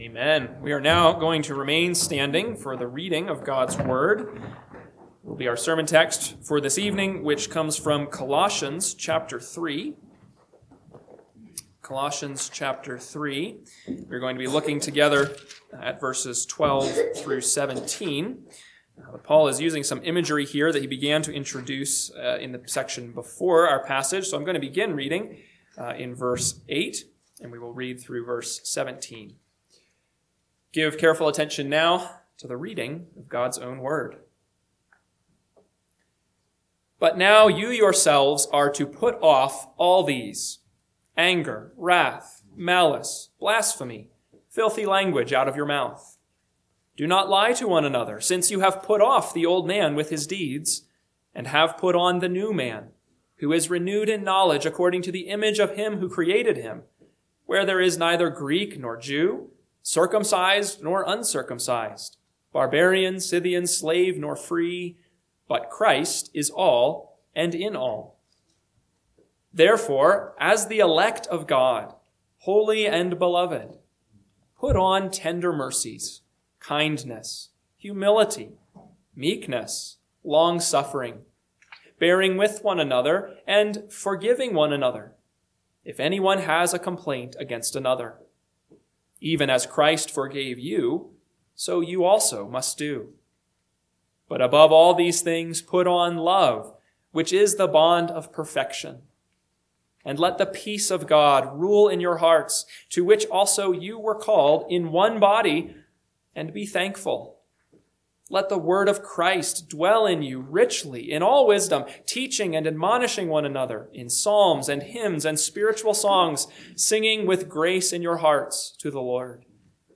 PM Sermon – 4/5/2026 – Colossians 3:12-17 – Northwoods Sermons